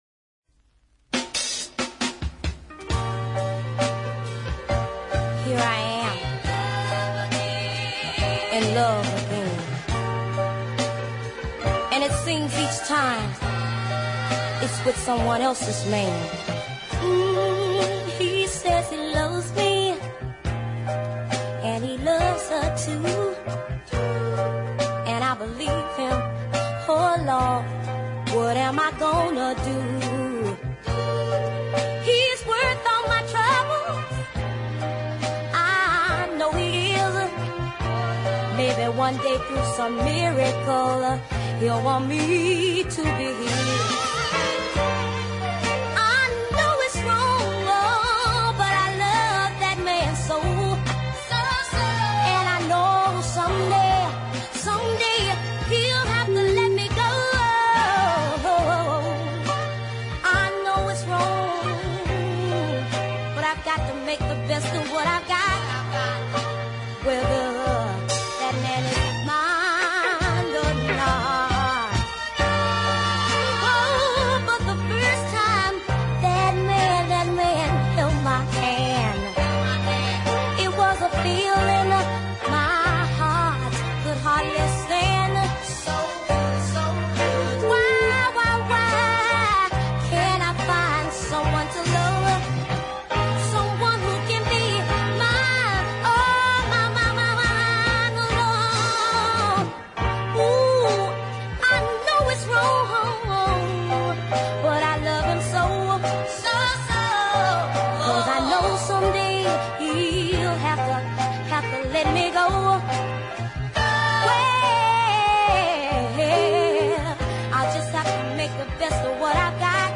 is a heartfelt ballad